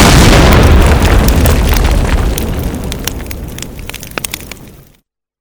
grenade-explode.wav